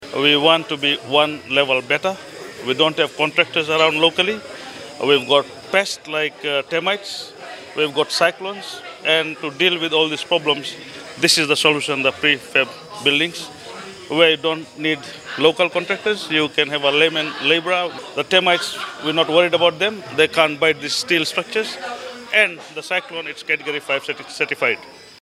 Businessman